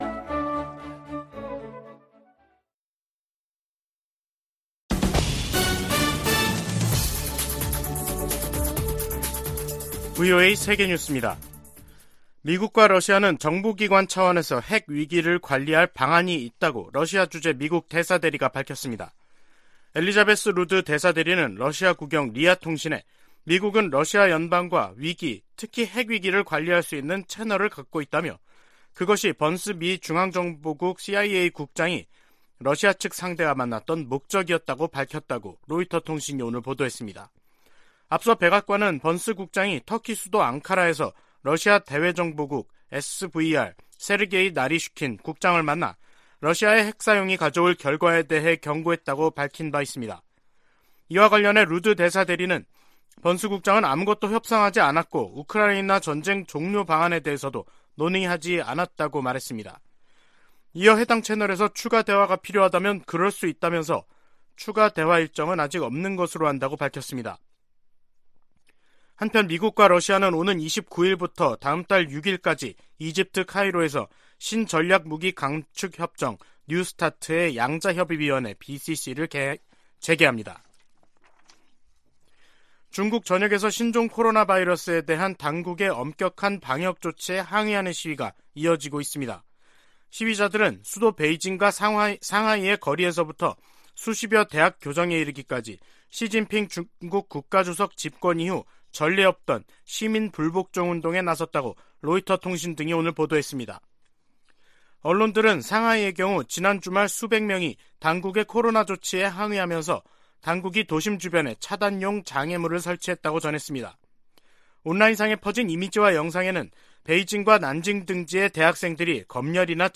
VOA 한국어 간판 뉴스 프로그램 '뉴스 투데이', 2022년 11월 28일 2부 방송입니다. 김정은 북한 국무위원장은 최근의 대륙간탄도미사일 시험발사를 현지 지도하며 대륙간 탄도미사일 부대를 처음 언급했습니다. 북한의 장거리 탄도미사일 발사가 미국 본토에 대한 위협이 되지 않으나 북한이 역내에 제기하는 위협을 우려한다고 백악관 고위 관리가 밝혔습니다.